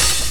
Index of /90_sSampleCDs/Best Service ProSamples vol.15 - Dance Drums [AKAI] 1CD/Partition B/HH 001-052